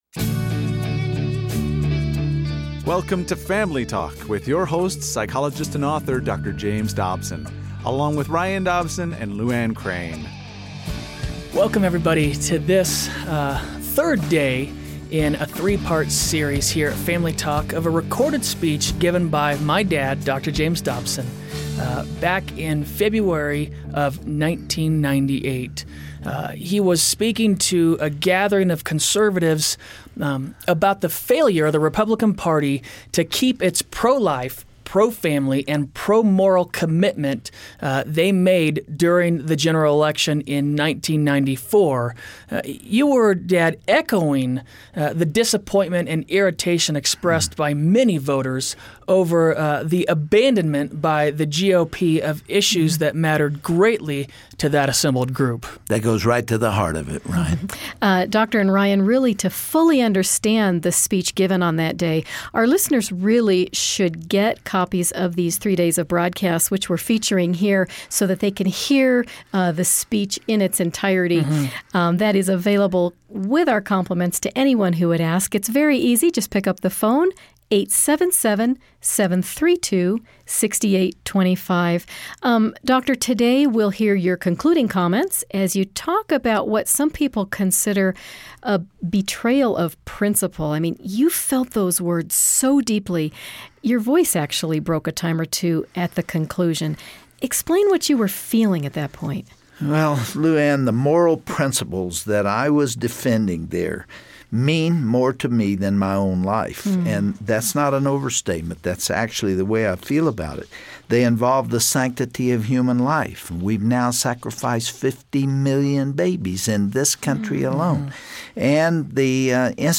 Dr. Dobson concludes his passionate address regarding morality in government by delivering a stern warning for conservative politicians. He urges our elected officials to cast their votes based on their convictions, not the latest polling data.